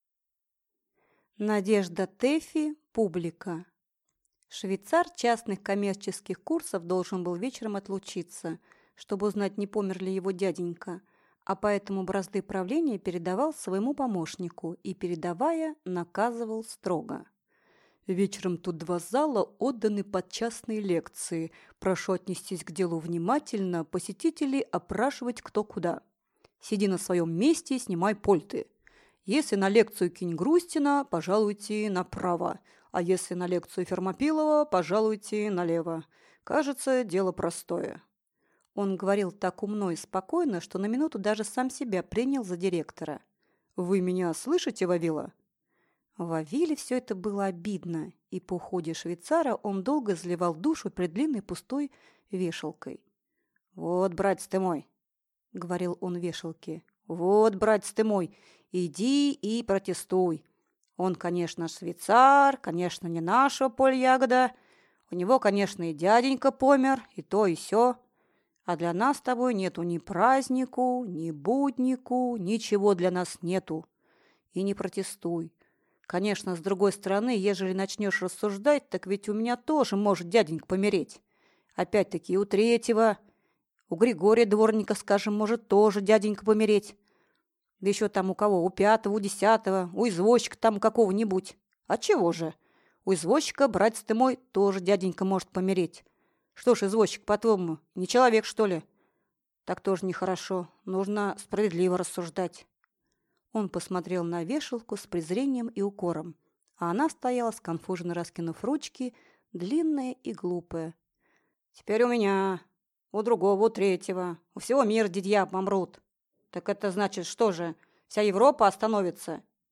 Аудиокнига Публика | Библиотека аудиокниг